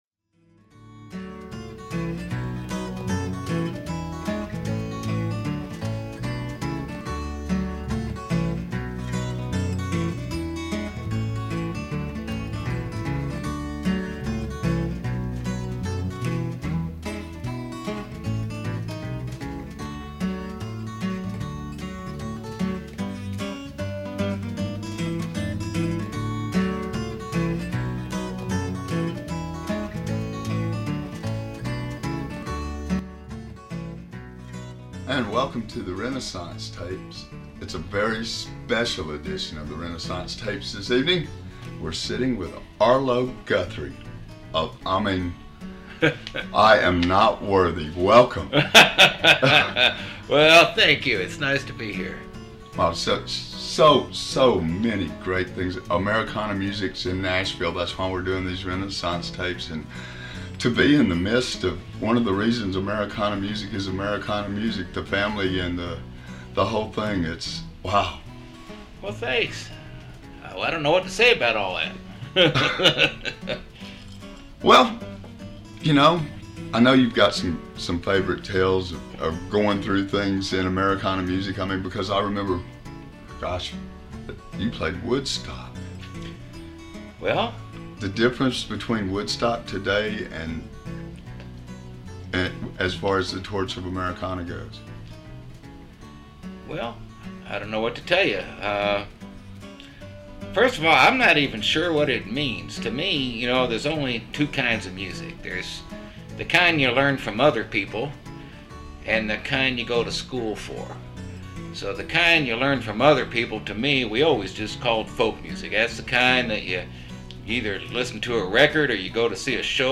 by Arlo Guthrie | Arlo Guthrie Interview